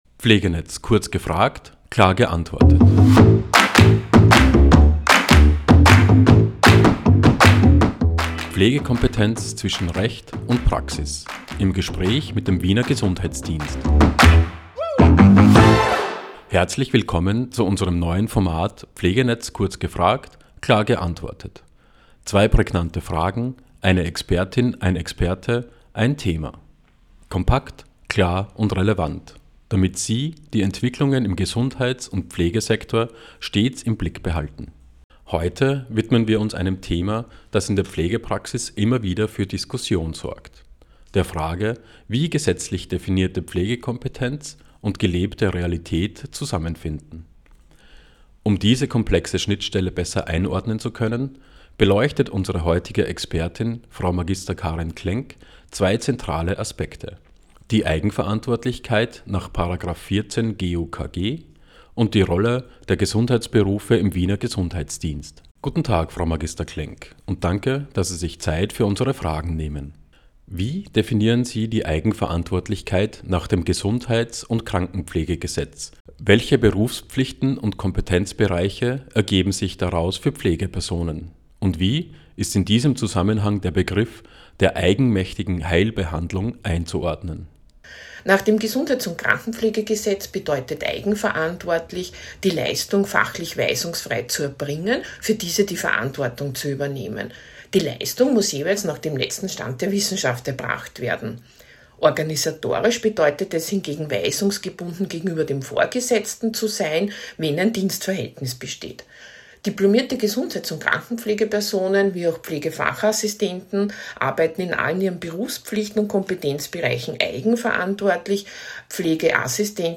– Pflegekompetenz zwischen Recht (§ 14 GuKG) & Praxis – im Gespräch mit dem Wiener Gesundheitsdienst